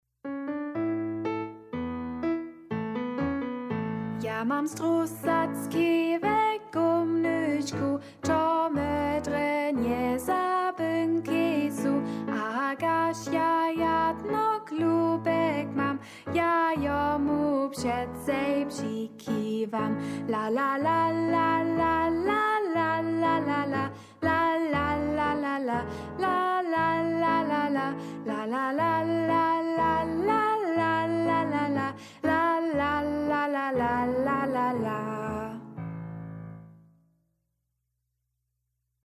tekst a melodija: z ludu